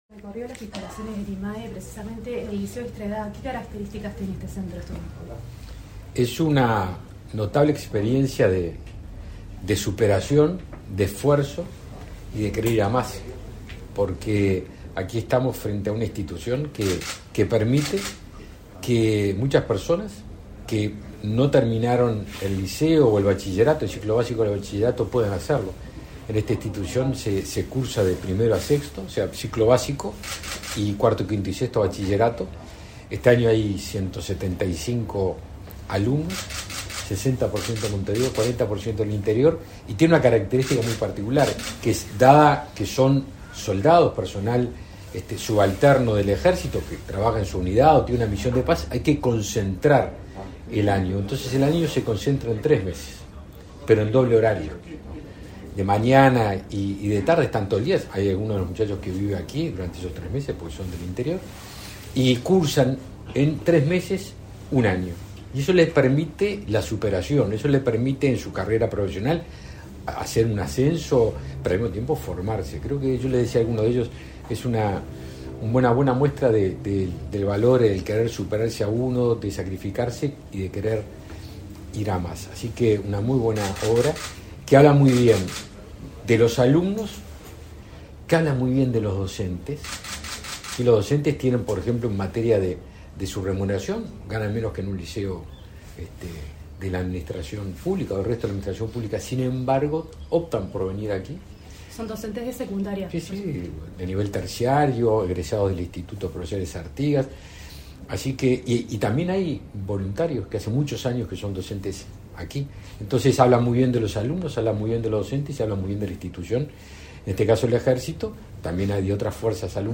Entrevista al ministro de Defensa Nacional, Javier García
Entrevista al ministro de Defensa Nacional, Javier García 07/07/2022 Compartir Facebook X Copiar enlace WhatsApp LinkedIn El ministro Javier García visitó, este 7de julio, el liceo extraedad que funciona en el Instituto de Armas y Especialidades del Ejército. En el lugar, el jerarca efectuó declaraciones a Comunicación Presidencial.